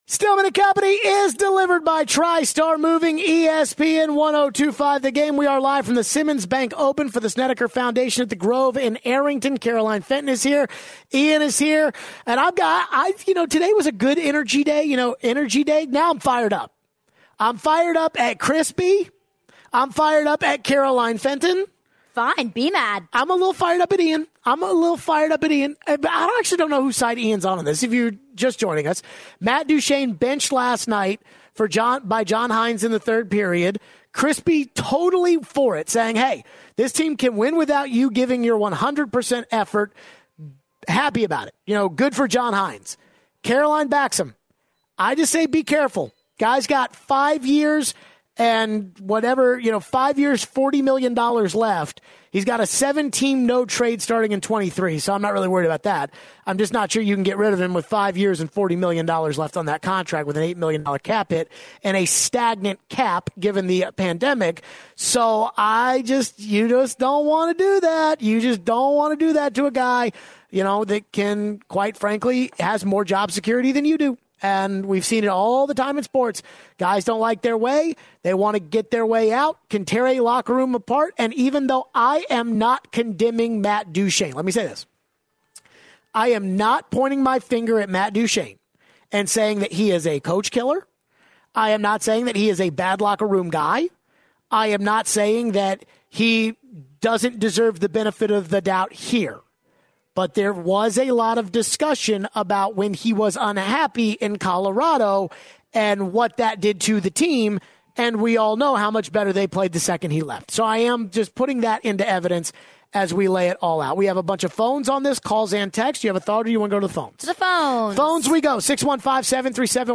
We carryover some of our Preds and Matt Duchene discussion. We take your calls and texts on the Preds.